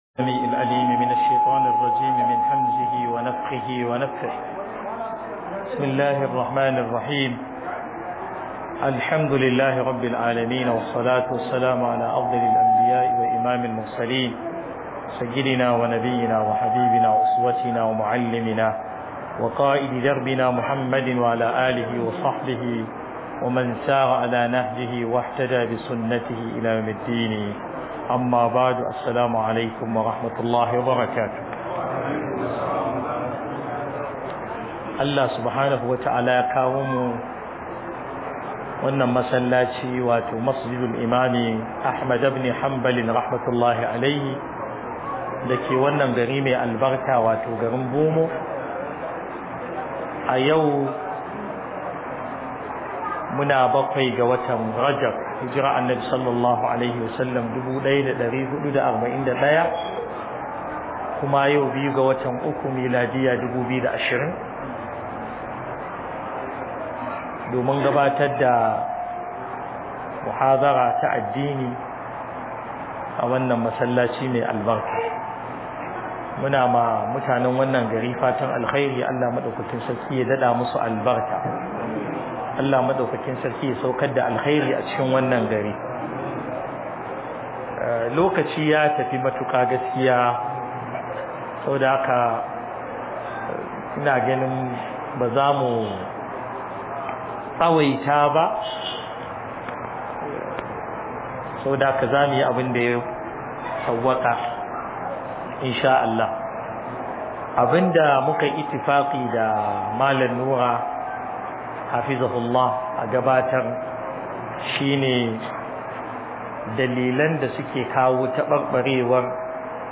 Sabubban lalacewar al`ummah_0 - Muhadarori Da Lakcoci